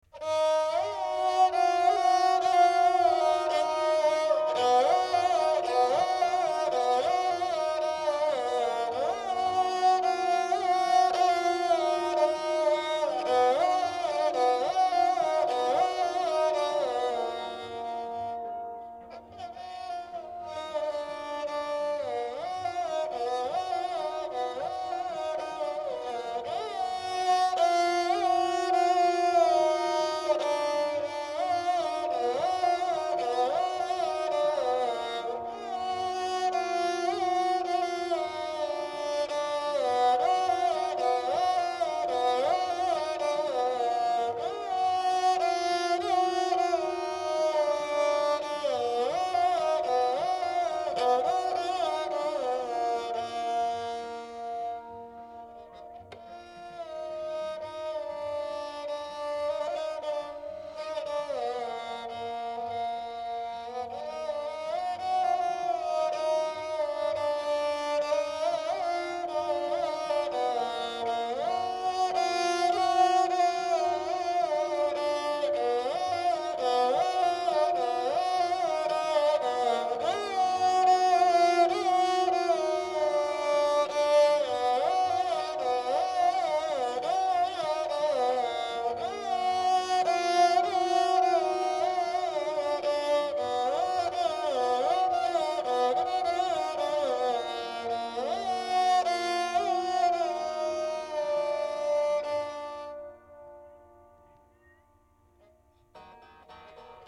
In raag Gauri Rishab and Dhaivat are komal surs.
Dilruba 3:
dilruba_gauri_3.mp3